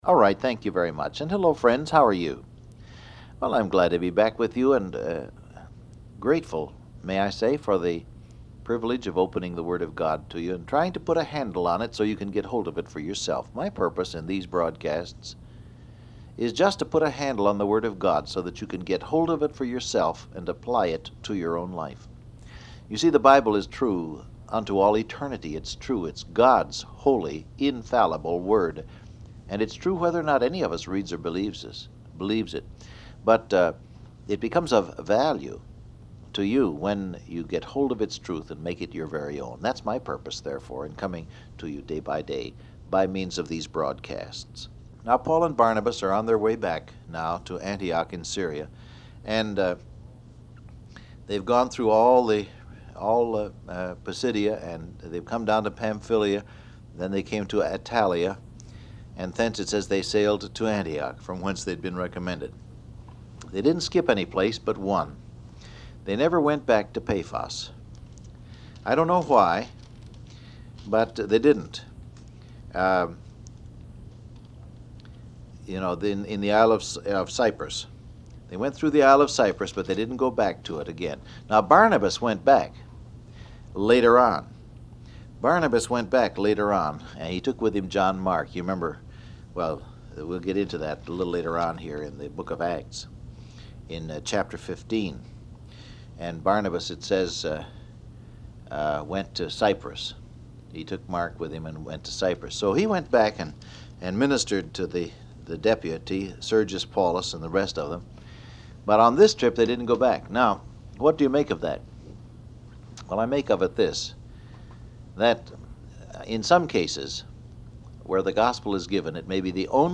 Download Audio Print Broadcast #7426 Scripture: Acts 15 , Colossians 4 Topics: Paul And Barnabas , Missionary , Fulfill Your Ministry Transcript Facebook Twitter WhatsApp Alright, thank you very much.